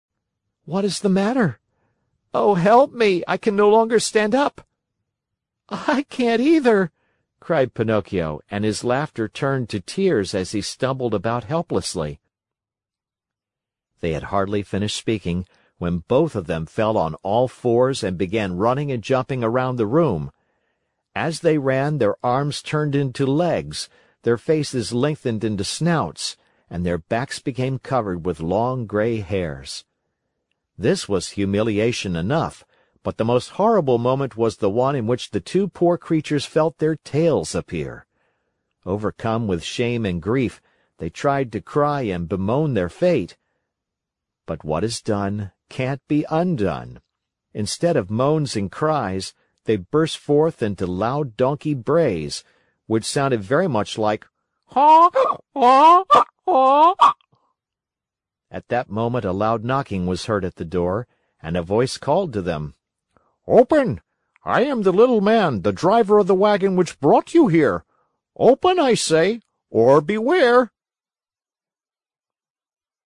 在线英语听力室木偶奇遇记 第128期:匹诺曹变成了一头驴子(9)的听力文件下载,《木偶奇遇记》是双语童话故事的有声读物，包含中英字幕以及英语听力MP3,是听故事学英语的极好素材。